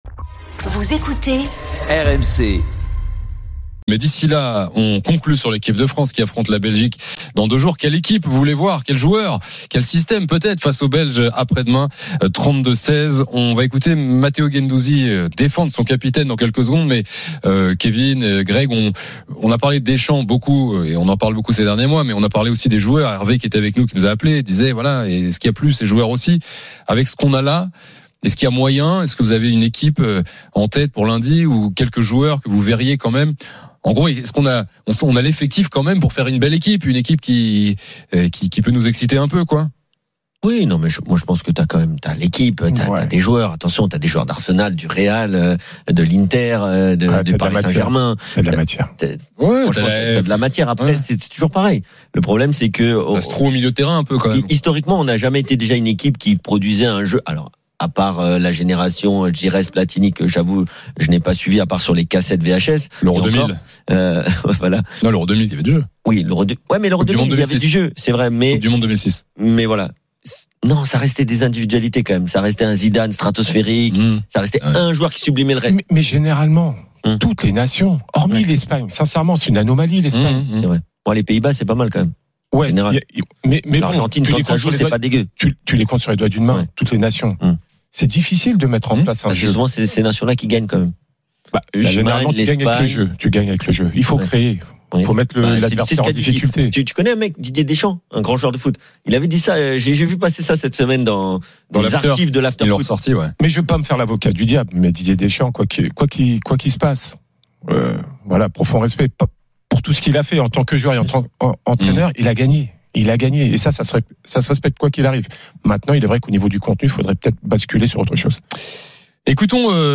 Chaque jour, écoutez le Best-of de l'Afterfoot, sur RMC la radio du Sport ! L’After foot, c’est LE show d’après-match et surtout la référence des fans de football depuis 19 ans !